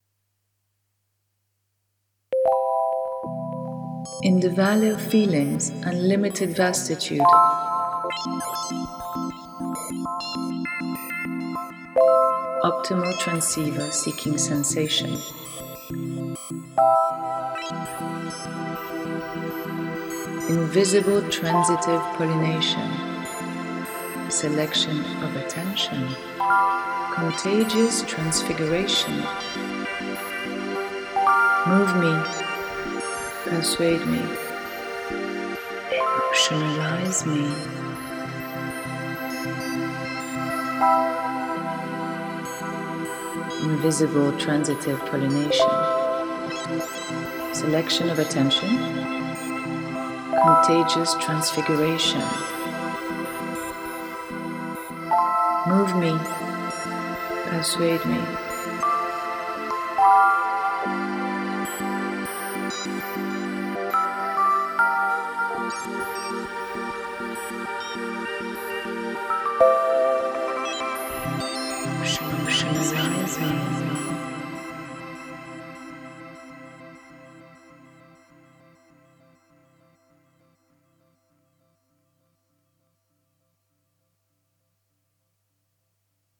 is a vibrant hommage to the 80s post-funk era